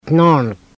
no:an : tiger snake (Tindale, N 1939)